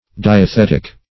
Search Result for " diathetic" : The Collaborative International Dictionary of English v.0.48: Diathetic \Di`a*thet"ic\, a. Pertaining to, or dependent on, a diathesis or special constitution of the body; as, diathetic disease.